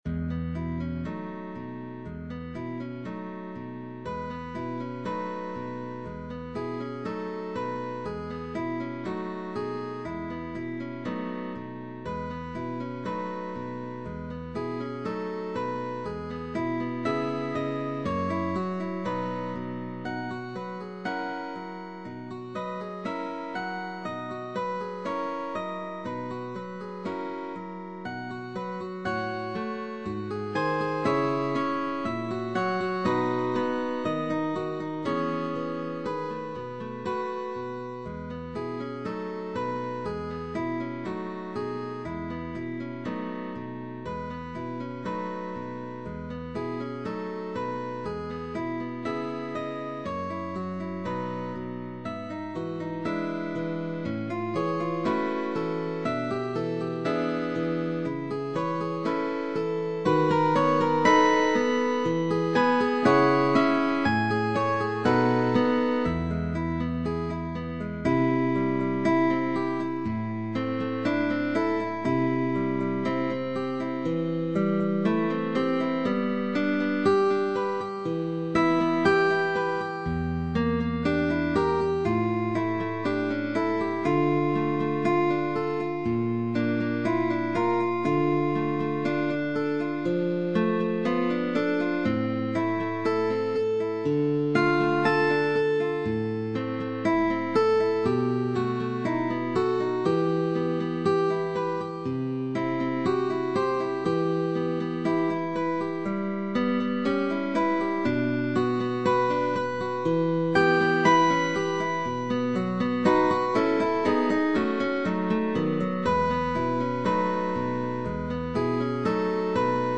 Changes of position, chords, slurs, arpeggios, …